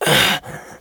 pain_9.ogg